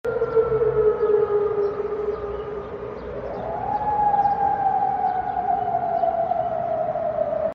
בירדן החלו להישמע אזעקות.